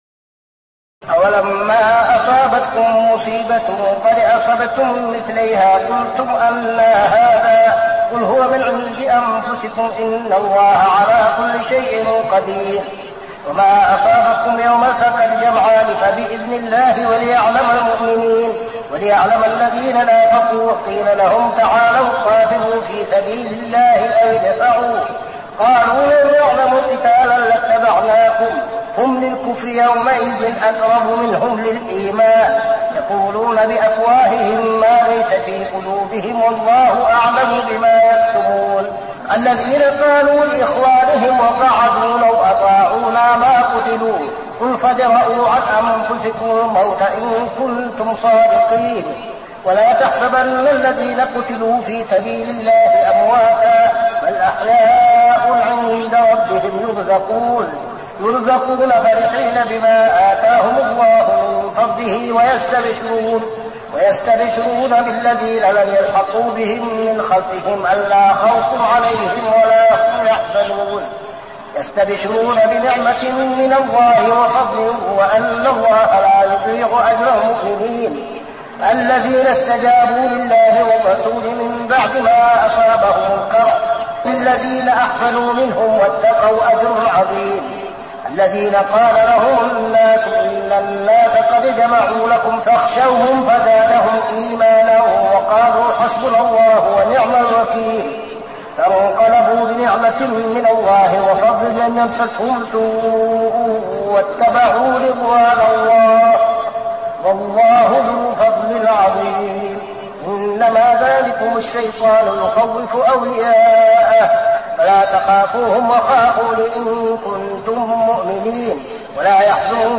صلاة التراويح ليلة 5-9-1402هـ سورتي آل عمران 165-200 و النساء 1-22 | Tarawih prayer Surah Al Imran and An-Nisa > تراويح الحرم النبوي عام 1402 🕌 > التراويح - تلاوات الحرمين